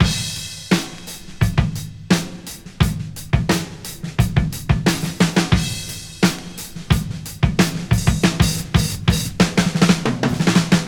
• 88 Bpm High Quality Breakbeat E Key.wav
Free drum loop sample - kick tuned to the E note. Loudest frequency: 1354Hz
88-bpm-high-quality-breakbeat-e-key-han.wav